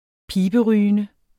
Udtale [ ˈpiːbəˌʁyːənə ]